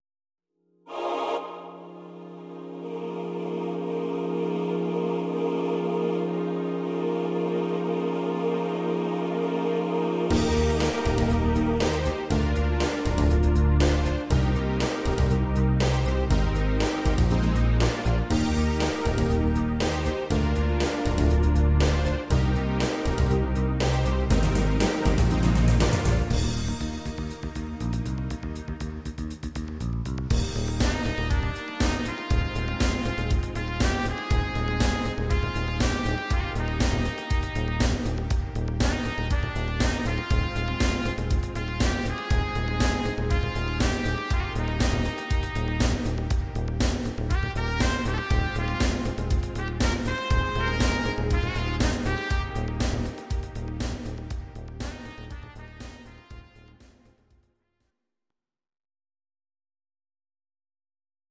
These are MP3 versions of our MIDI file catalogue.
Please note: no vocals and no karaoke included.
'87 Disco version